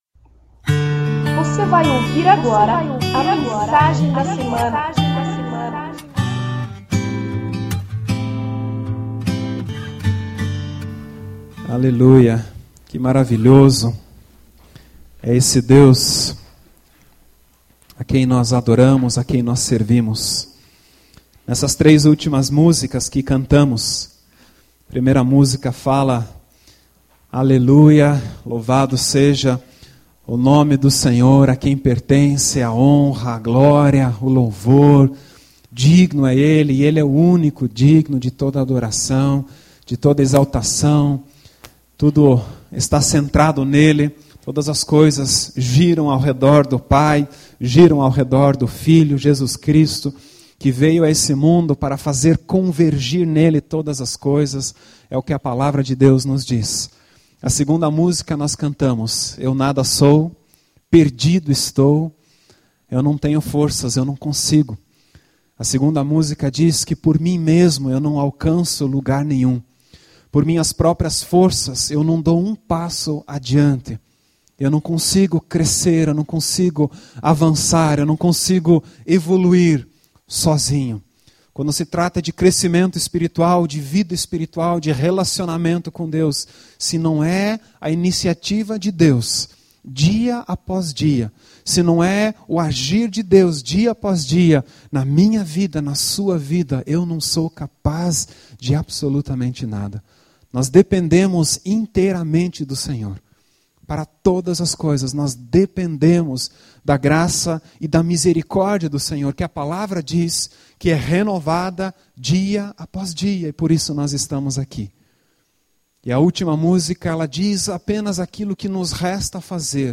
mensagem da semana - Igreja Evangélica Menonita - Água Verde